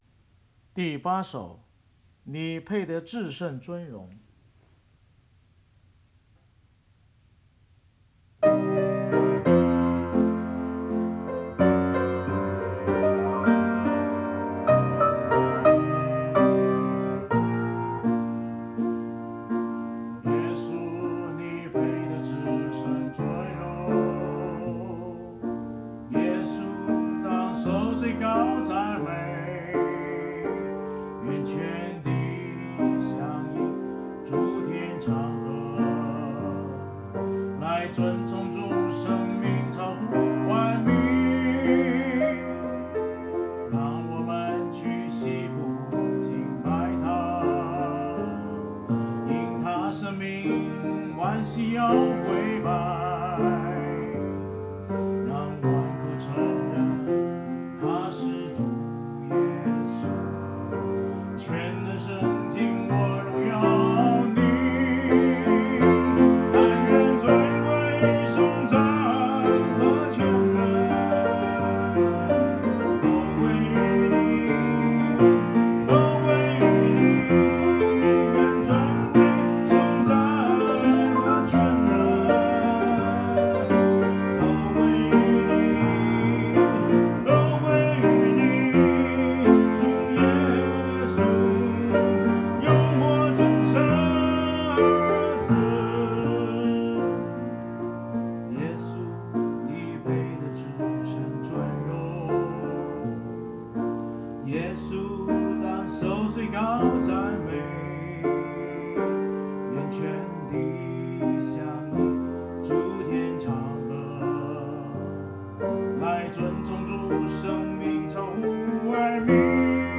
诗歌敬拜-2025年04月06日